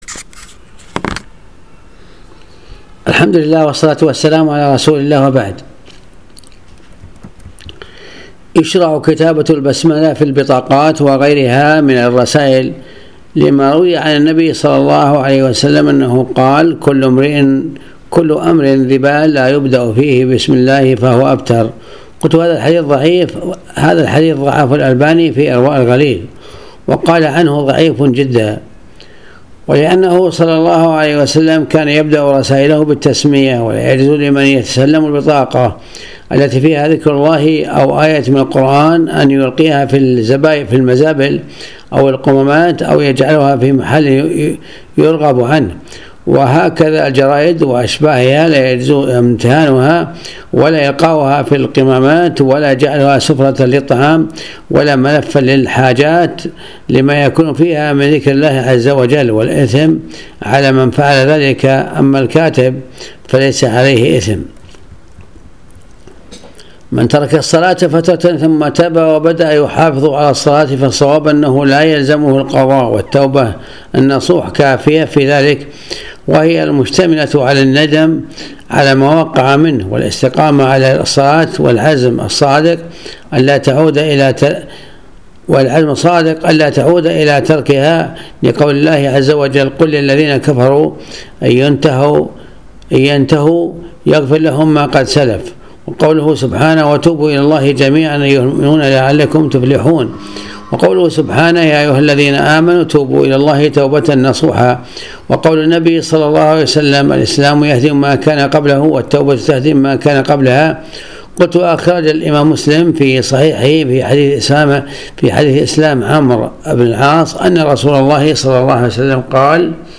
المحاضرة